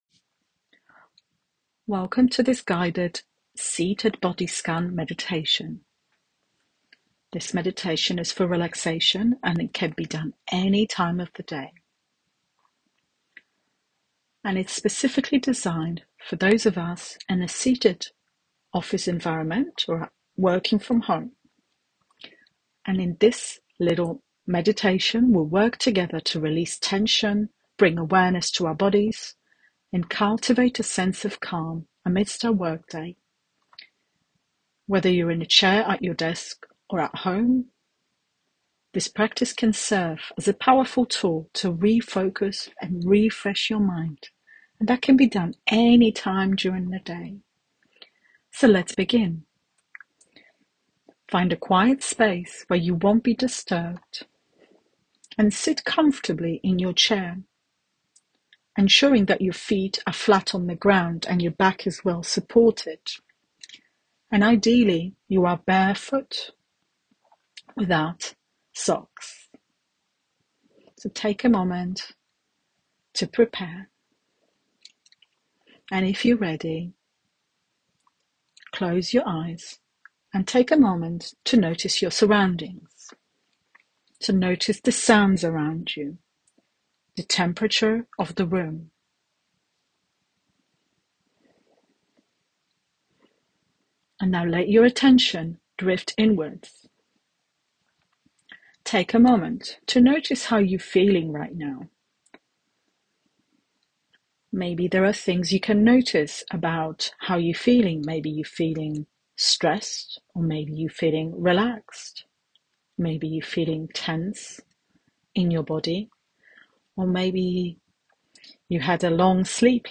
This calming yet invigorating session is designed to gently ease you into a state of present awareness, allowing you to begin your day with clarity and focus.
Morning-Meditation.m4a